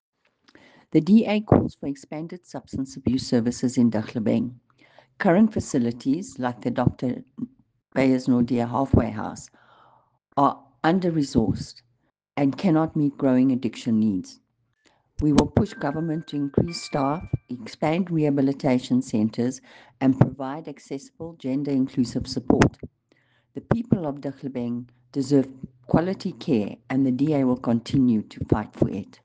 English and Afrikaans soundbites by Cllr Irene Rügheimer and